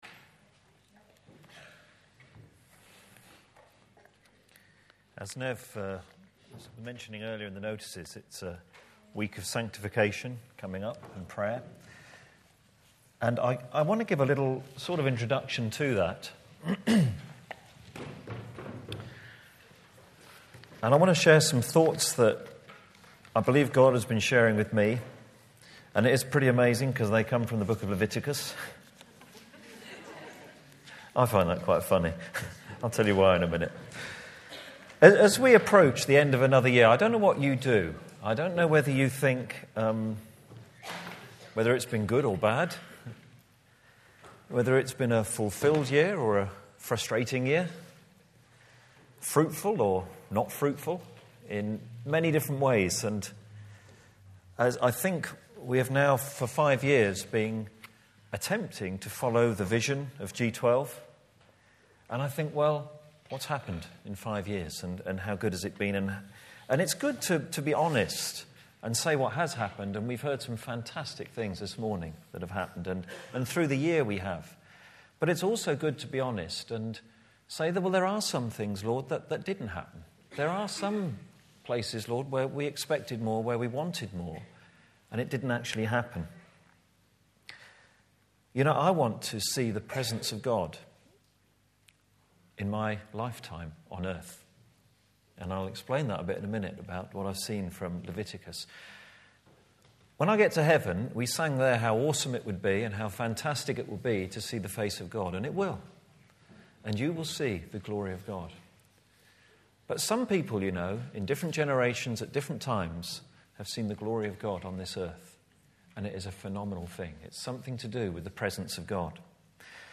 Listen back to all Sunday morning talks below.